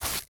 Grass Running.wav